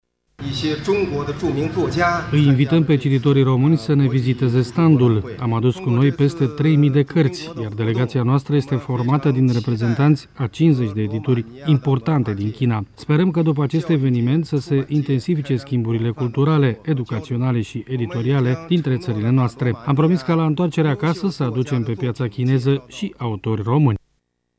Ministrul adjunct pentru Cultură şi Mass-media din China, Ien Şohong: